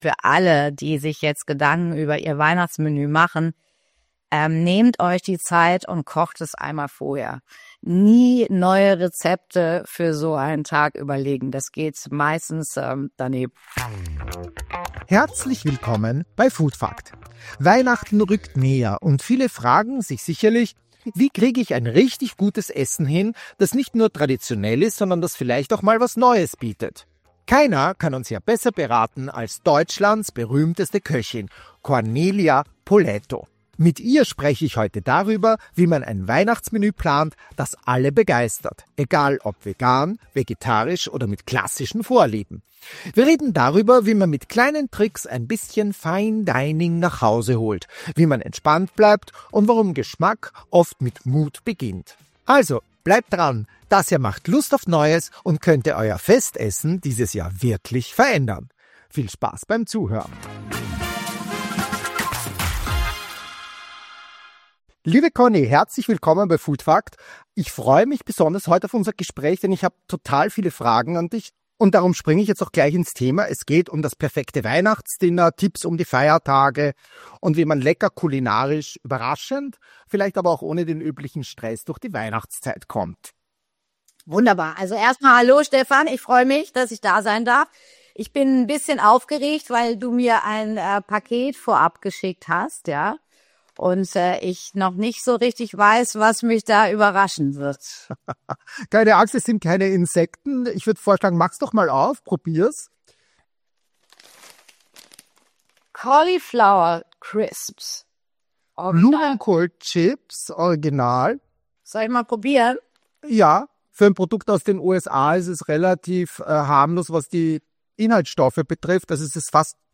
Ein warmes, inspirierendes Gespräch über Genuss, Timing und die Magie eines gemeinsamen Essens.